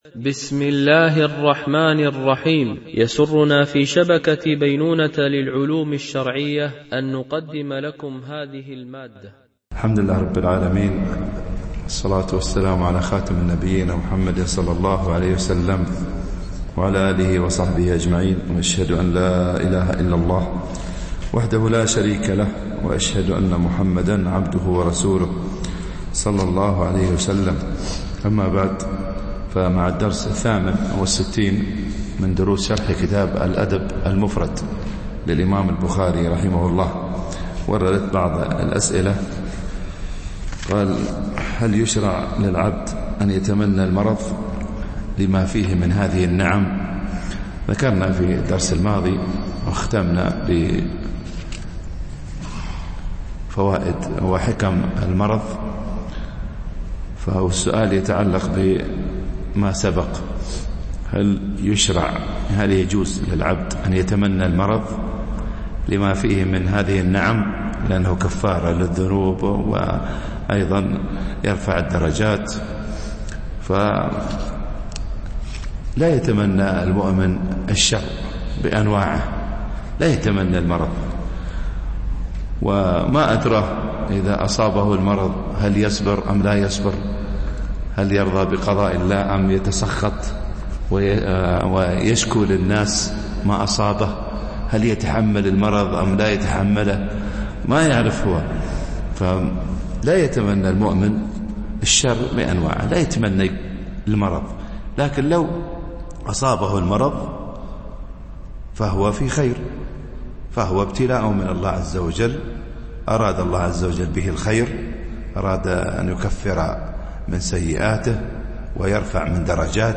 شرح الأدب المفرد للبخاري ـ الدرس 68 ( الحديث 496 -508)